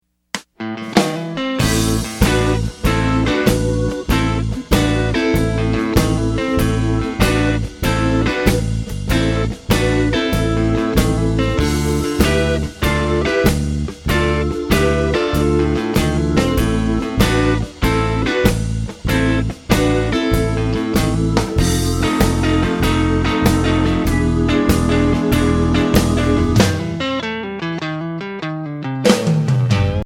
Voicing: Guitar Tab